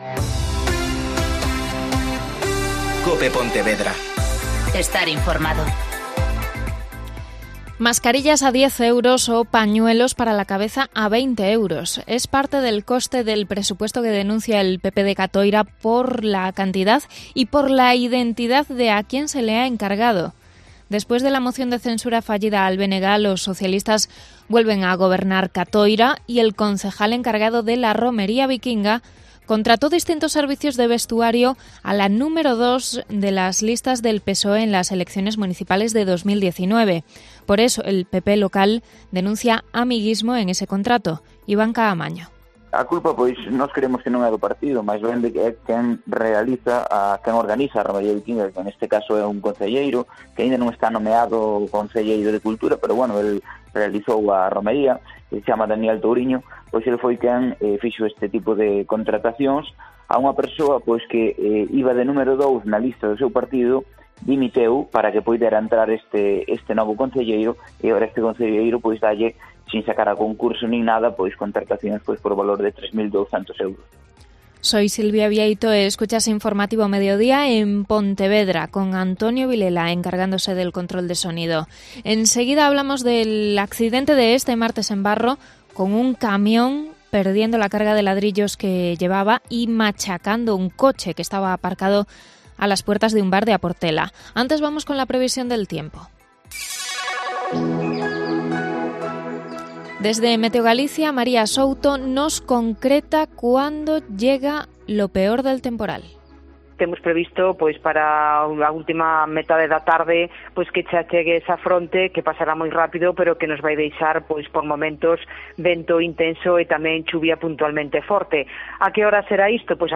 Mediodia COPE Pontevedra (Informativo 14:20h)